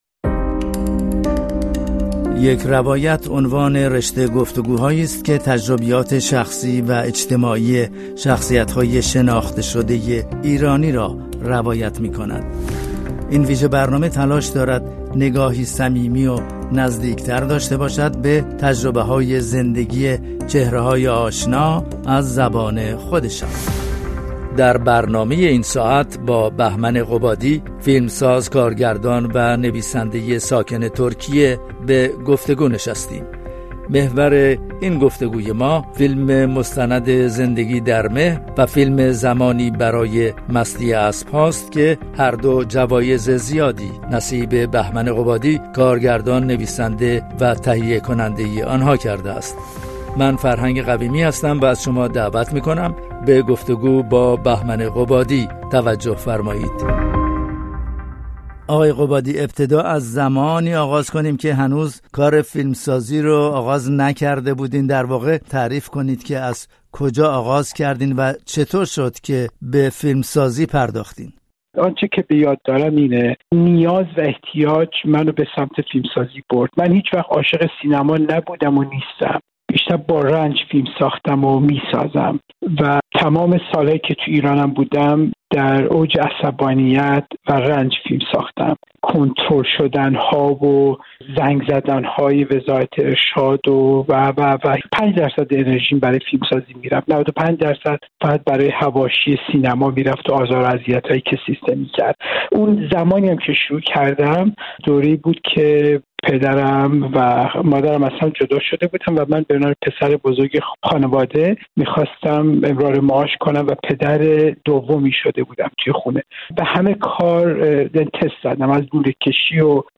یک جور دهن‌کجی به مرگ؛ گفت‌وگو با بهمن قبادی